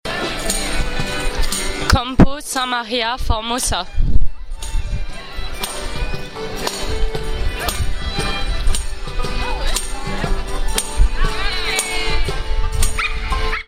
Sur la place Maria formosa, un groupe de chanteur.